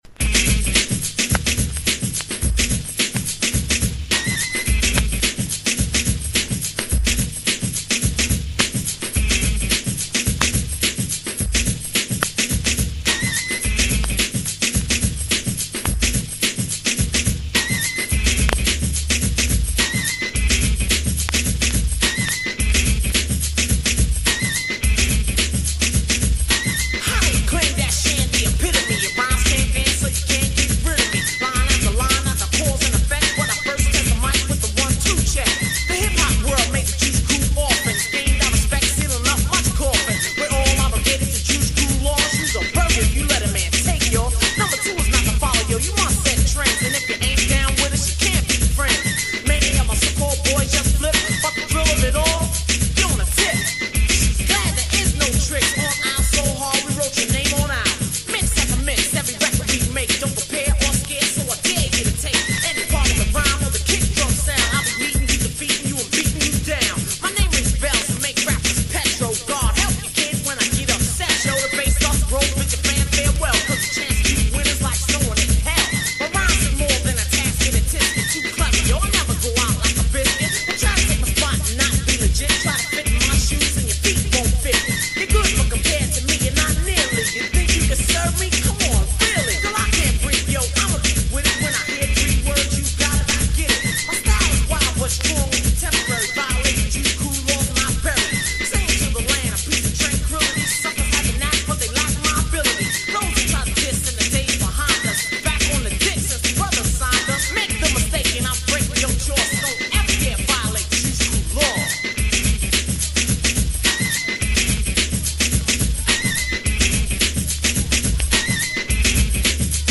For those who like their underground cultural Hip-Hop !!!! .